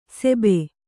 ♪ sebe